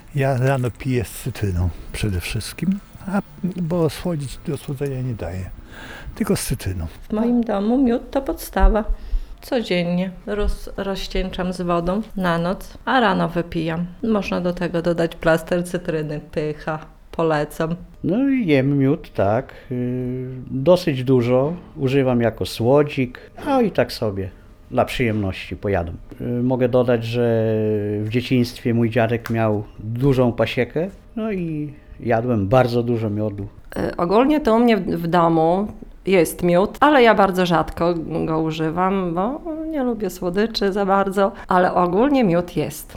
Warto pamiętać o tym, że miód w łyżce stołowej zawiera 60 kcal. i warto dopasować ilość jego spożywania do ilości energii jaką tracimy każdego dnia. Spożywany jest dla lepszego zdrowia, ale także znalazł zastosowanie w kosmetyce – mówili spotkani mieszkańcy Suwałk